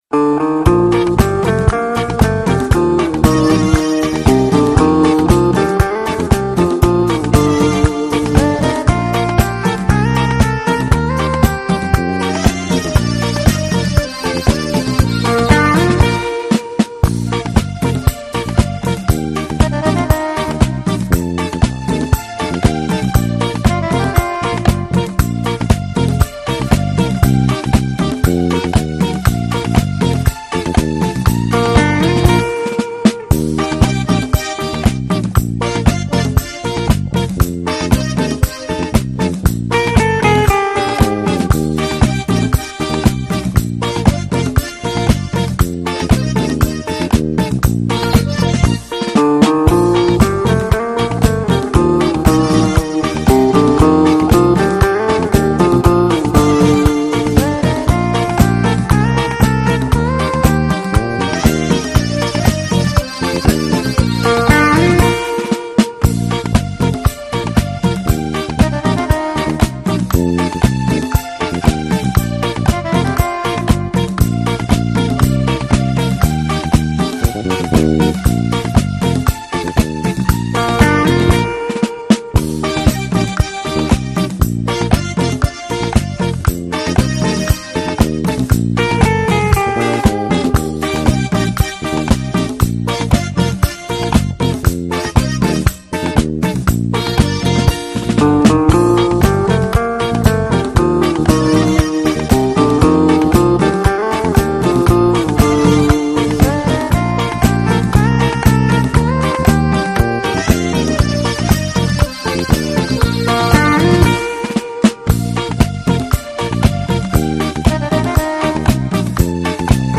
No Voice Karaoke Track Mp3 Download